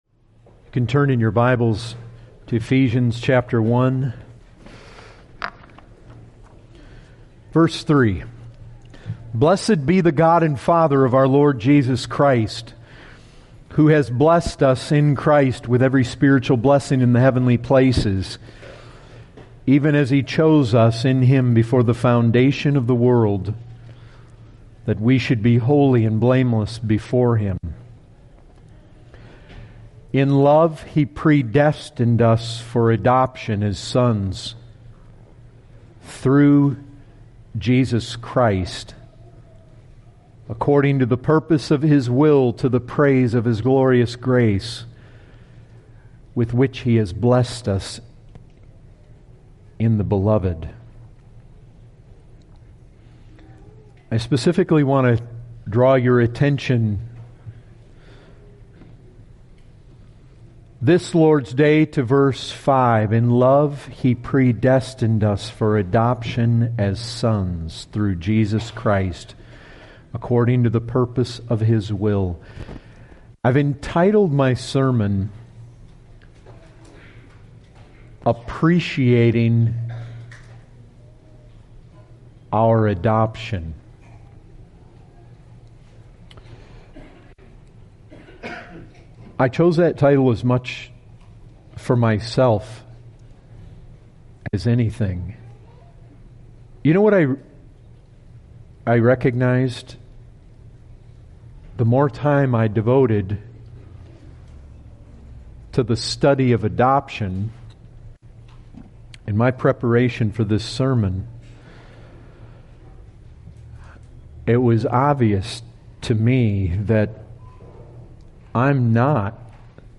This sermon is an attempt to bring Christians face to face with the precious truth that God not only acquits the sinner of his sin when he is saved, but brings the forgiven sinner into His own family.